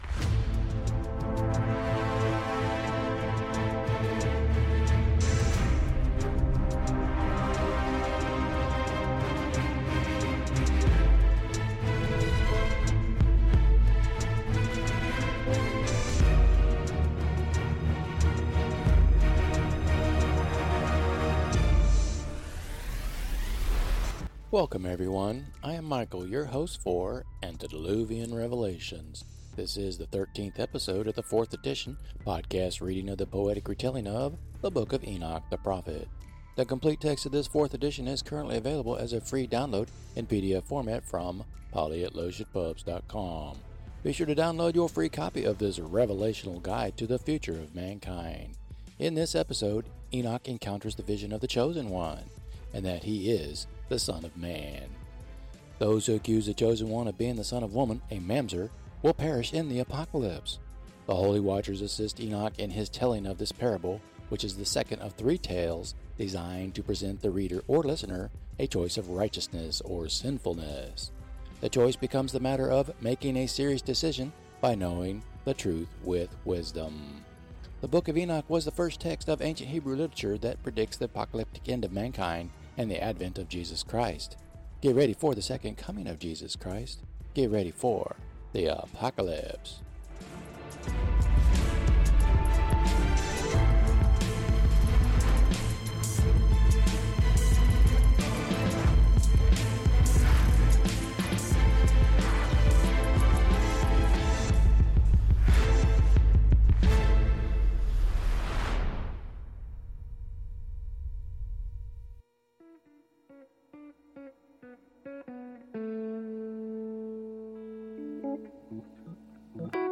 This is the thirteenth episode in this fourth edition of the podcast reading of the poetry within the book. These episodes will present Part Two of the epic poem, and the music selection will get a Jazz and Rock infusion.